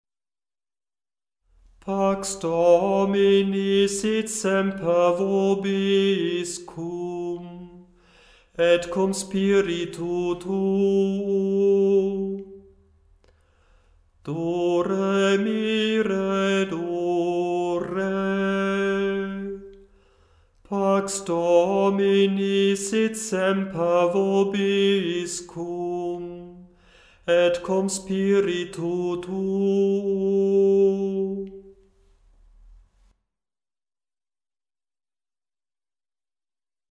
Die Gesänge sind in der Tonhöhe absteigend geordnet, zuerst deutsch, danach latein
pax-domini_gl-589-7_erster-ton_g4.mp3